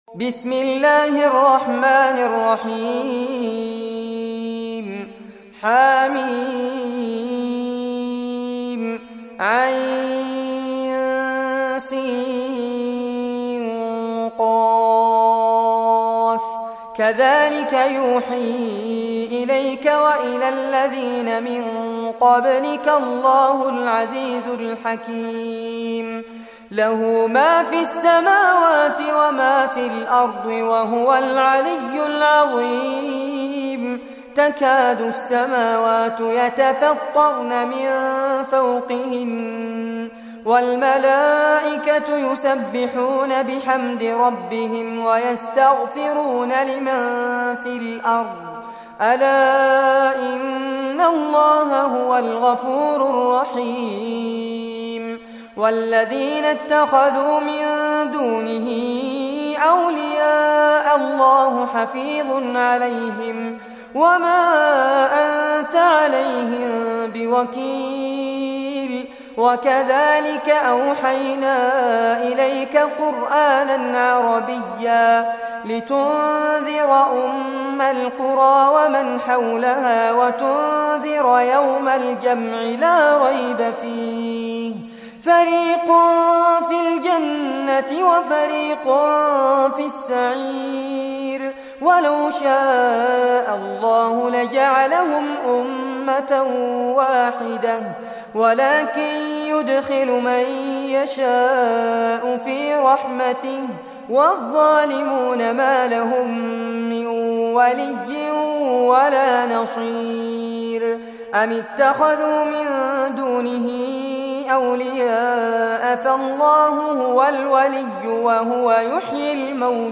Surah Ash-Shuraa Recitation by Fares Abbad
Surah Ash-Shuraa, listen or play online mp3 tilawat / recitation in Arabic in the beautiful voice of Sheikh Fares Abbad.